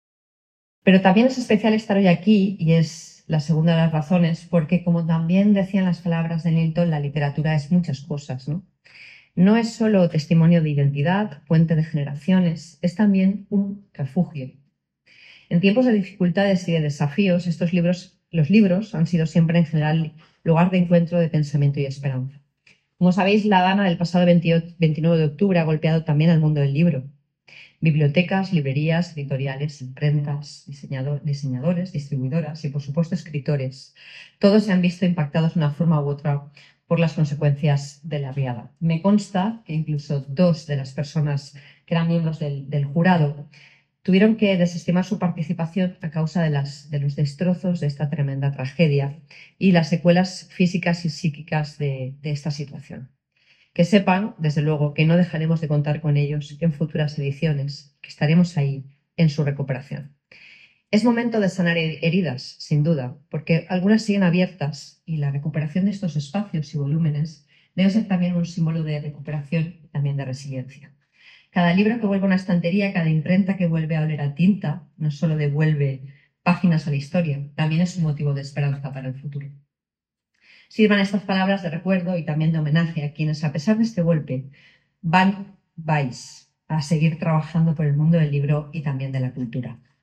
El acto se ha desarrollado en el Salón de Plenos de la Casa Consistorial, al que han acudido además de las personas galardonadas, representantes de la corporación municipal, del sector editorial y del mundo de las letras y la cultura en general. La alcaldesa ha felicitado a los creadores y creadoras distinguidos así como a los autores de las casi 1.960 obras presentadas a la convocatoria de los premios en 2024.